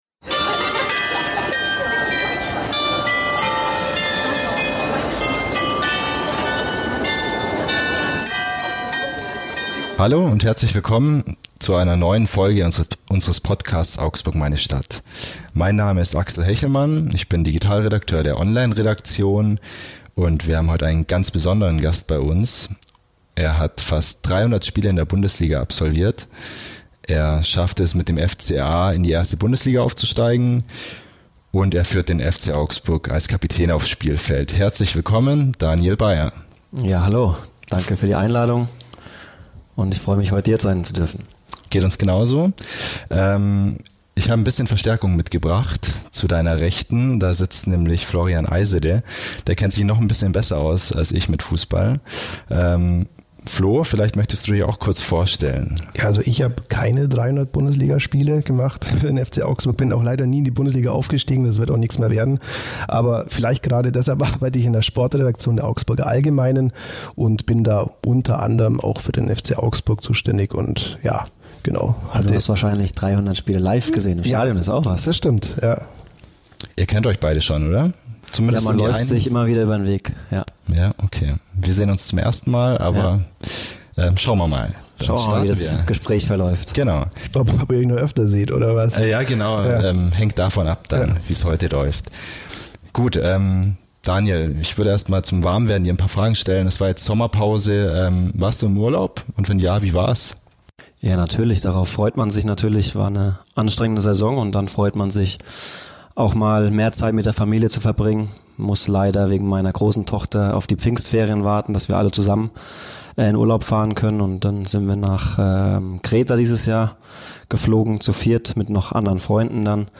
Dabei wirkt Baier offen und reflektiert. Er gesteht eigene Fehler ein, kritisiert die Geld-Maschinerie im Profi-Fußball und spricht über Grenzen der Privatsphäre in der Öffentlichkeit.